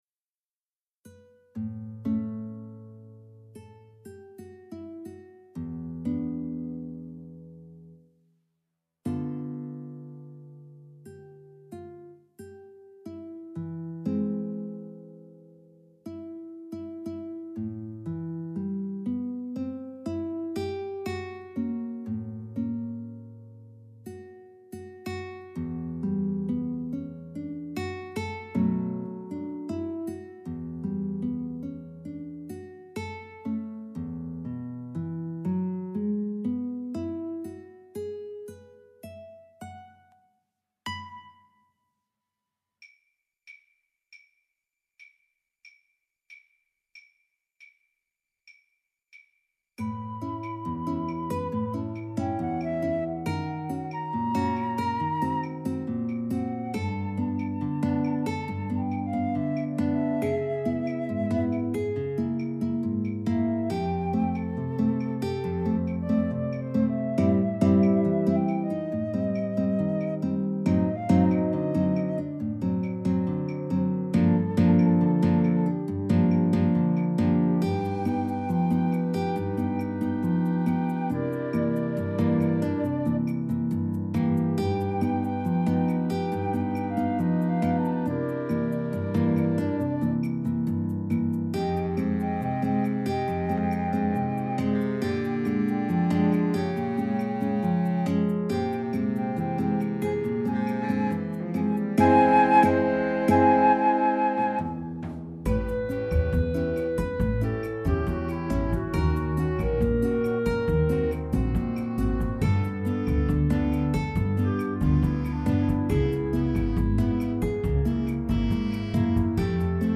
Guitare Solo et Orchestre d'Harmonie